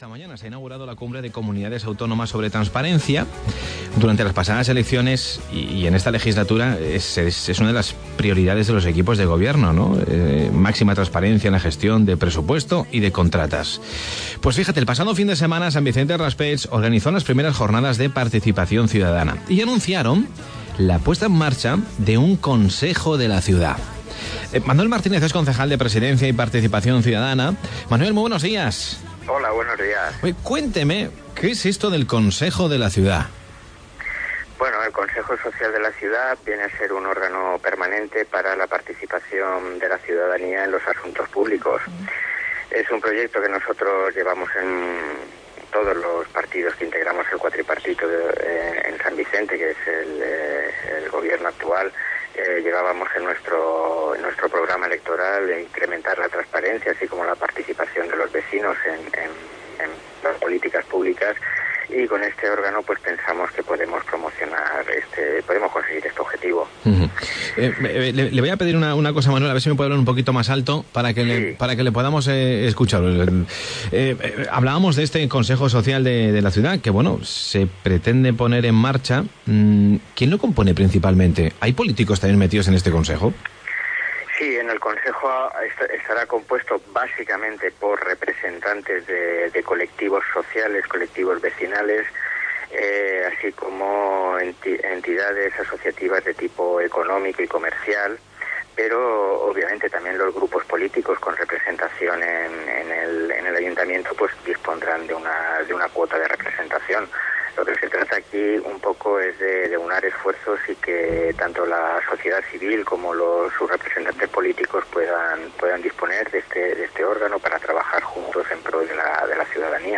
El Concejal de Participación Ciudadana, Manuel Martínez, explica como será el Consejo Social de la Ciudad en San Vicente del Raspeig en Cope Alicante.
Os dejamos el audio integro de su intervención.